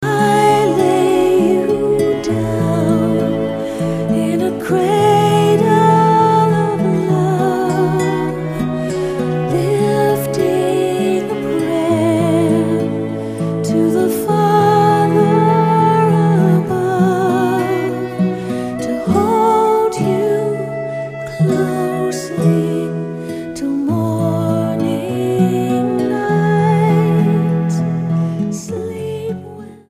STYLE: Celtic
traditional Celtic instrumentation with soothing lullabies